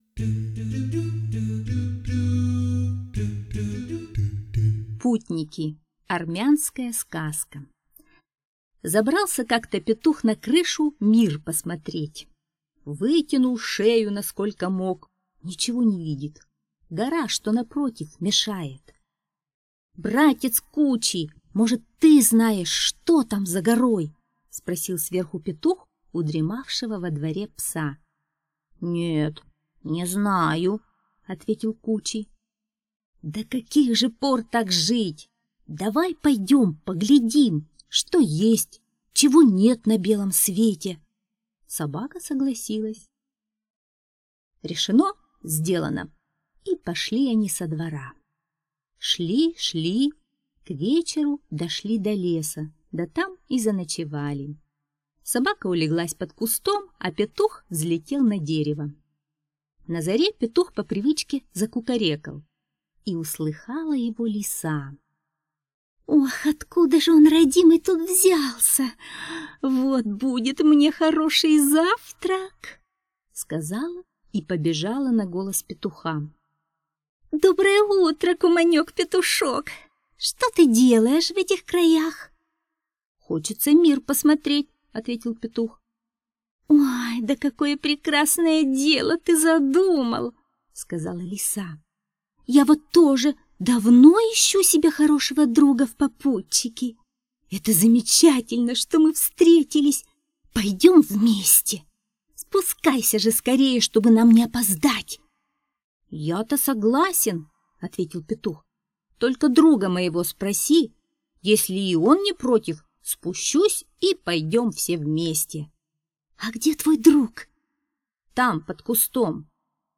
Путники - армянская аудиосказка - слушайте онлайн